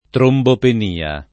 trombopenia [ trombopen & a ]